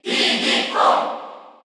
Crowd cheers (SSBU) You cannot overwrite this file.
Diddy_Kong_Cheer_Korean_SSBU.ogg